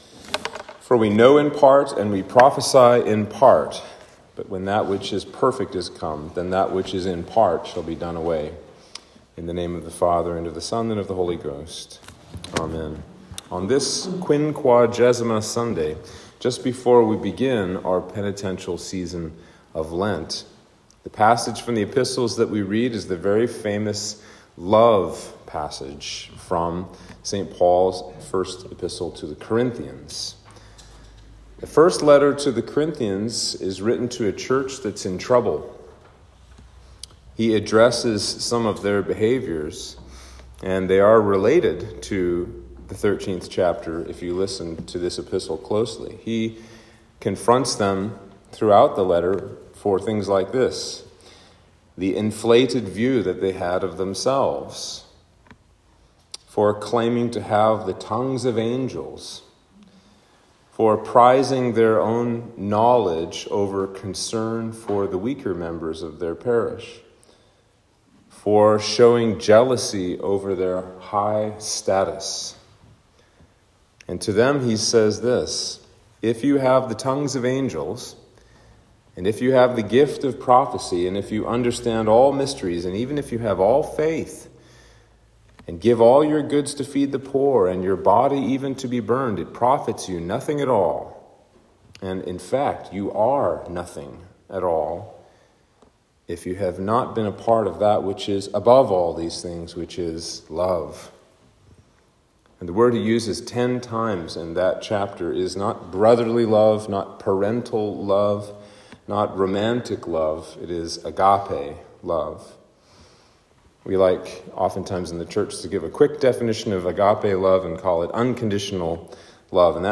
Sermon for Quinquagesima